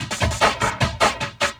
45 LOOP 02-R.wav